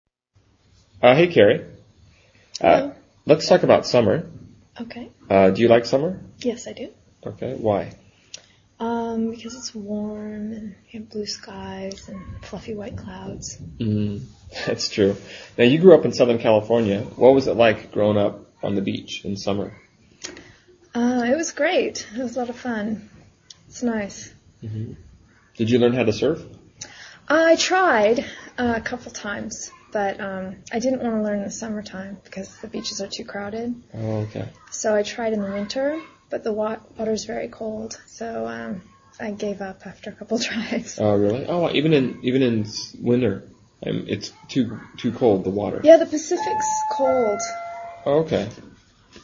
英语高级口语对话正常语速05:夏天（MP3）